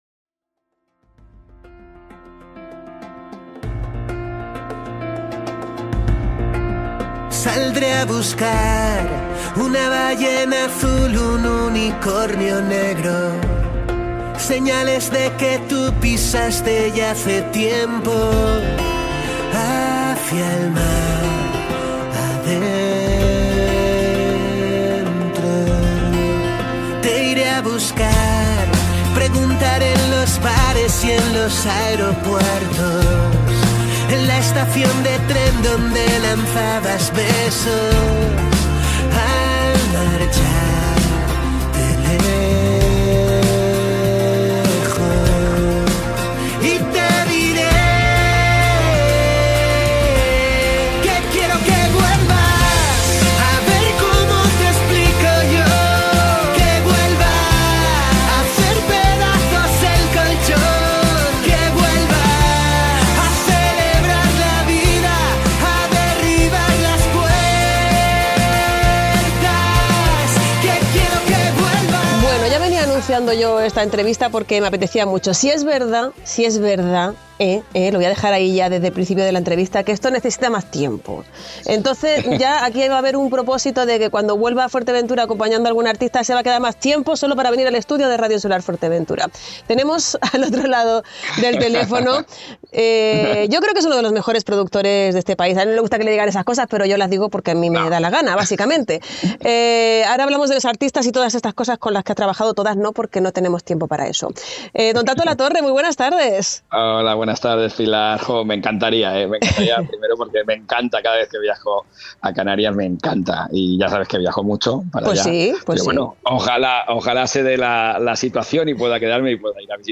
El programa El Tardeo de Radio Insular Fuerteventura recibió este martes a uno de los grandes nombres de la música española